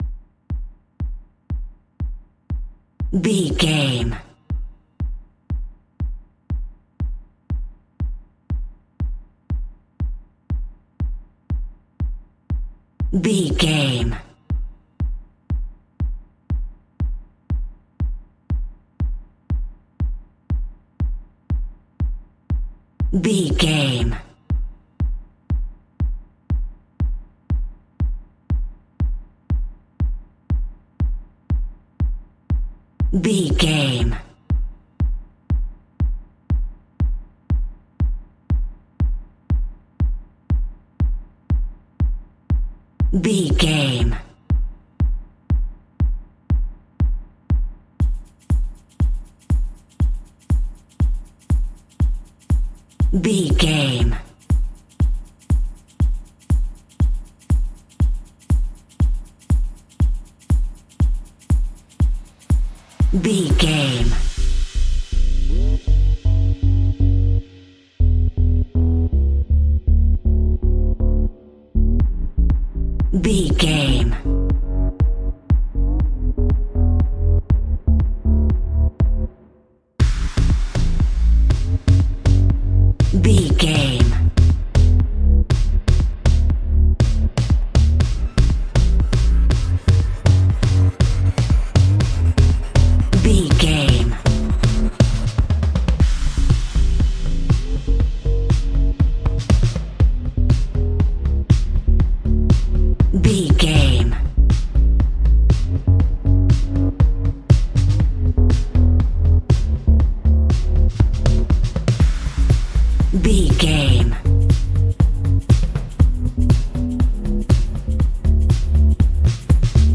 Ionian/Major
bright
energetic
bass guitar